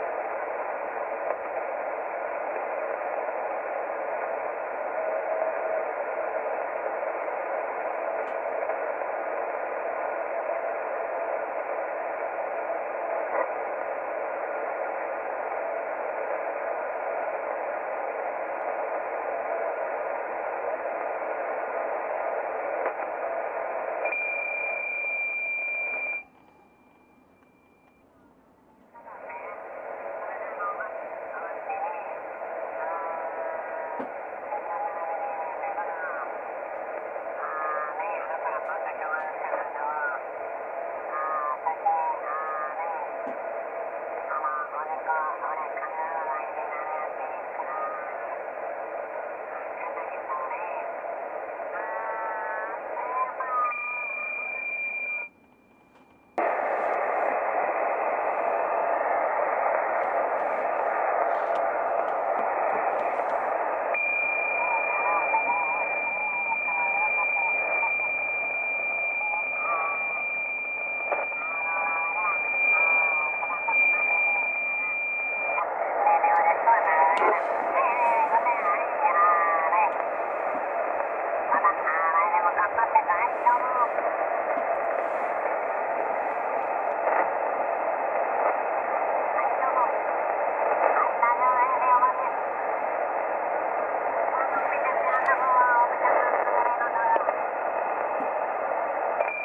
maximu, kolem 7:00 UTC, bylo kolem kmitočtu spousta QRM (PSK31, SSB italští
nejmenším QRM (v jednom souboru).
Pro úplnost - anténa na kopci je Windomka, cca 6m vysoko.